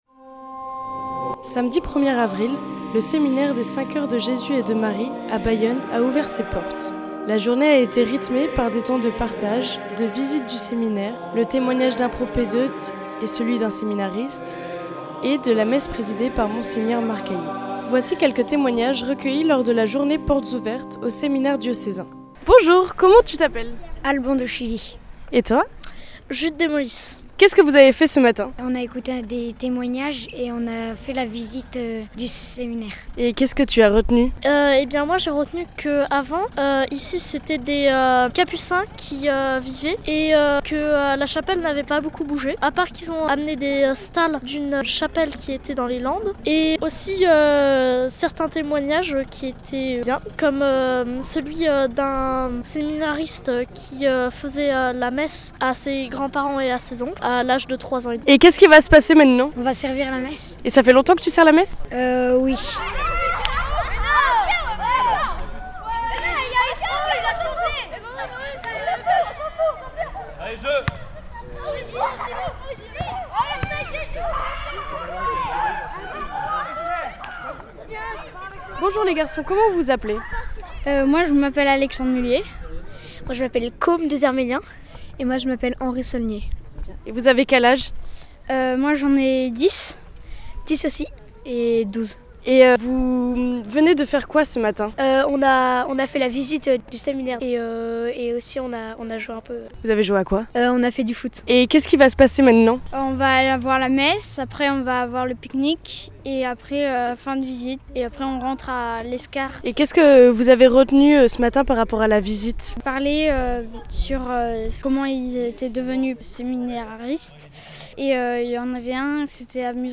Témoignages recueillis